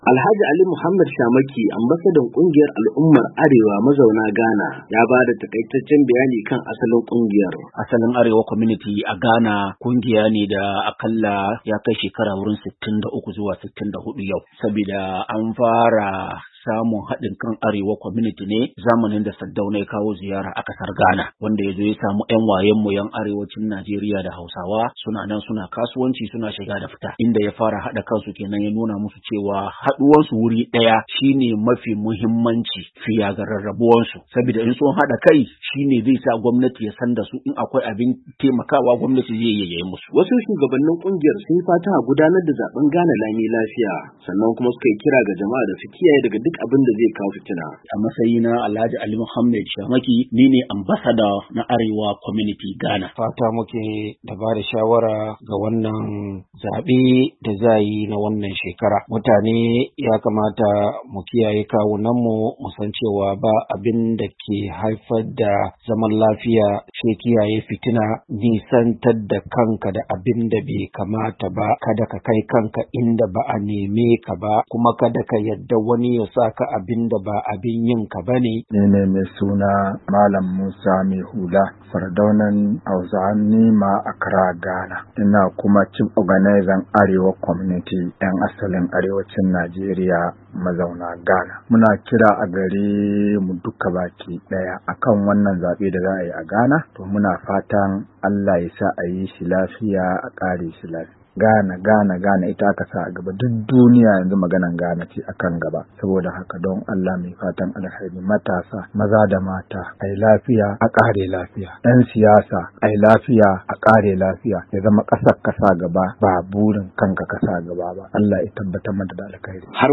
ACCRA, GHANA —